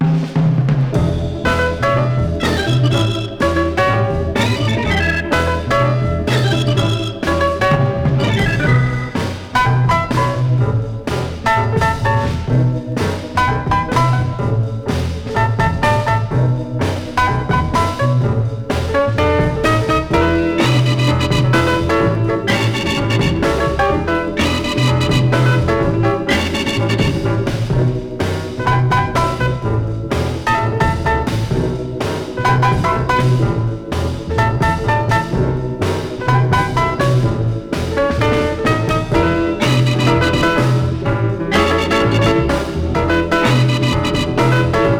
グルーヴィーでヒップな演奏、小編成で色彩豊かなアレンジ、遊び心満載のエキサイティングな好盤。
Jazz, Soul-Jazz, Easy Listening　USA　12inchレコード　33rpm　Stereo